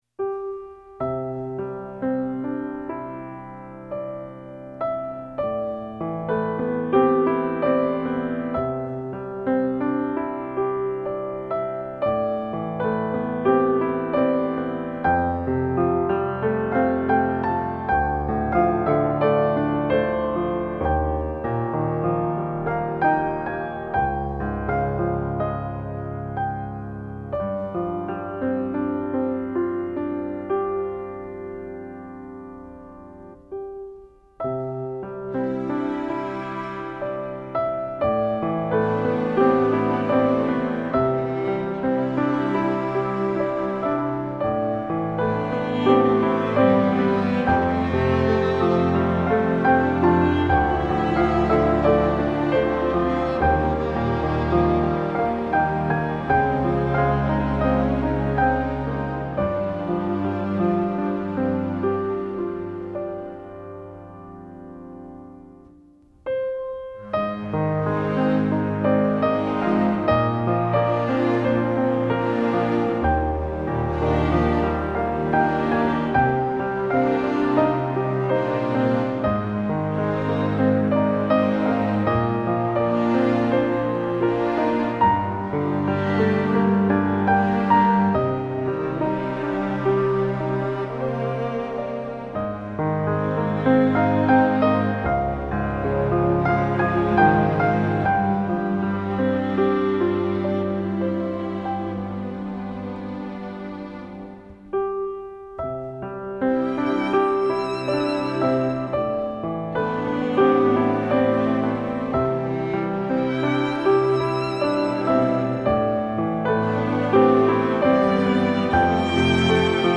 一本音樂童畫書
有鋼琴、弦樂、豎笛童聲等等樂器